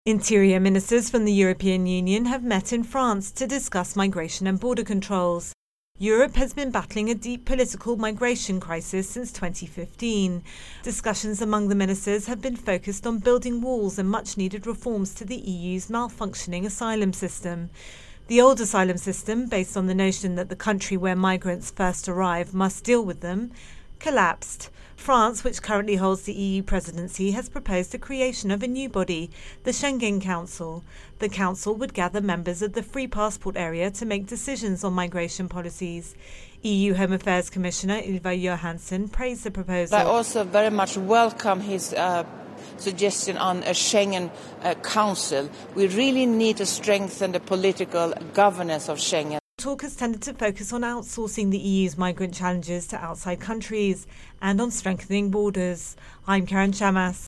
Intro and voicer on 'Migration Europe'.